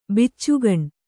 ♪ biccugaṇ